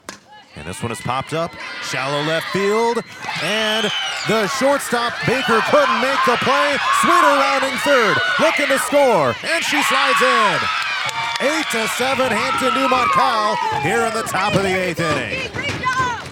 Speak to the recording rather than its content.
In Friday’s Battle for Bradford softball game that aired on 104.9 KLMJ, late game heroics gave both teams a chance to win.